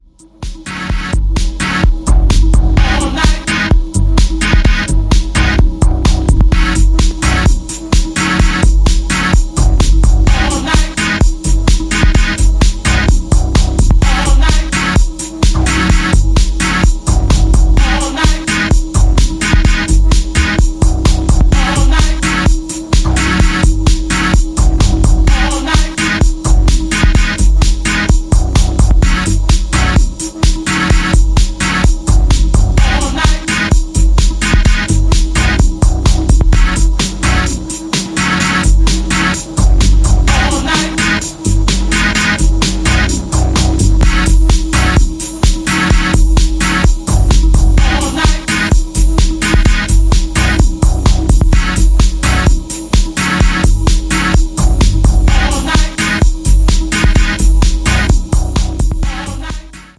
NYCディープ・ハウスのミニマルヴァージョン”B2”。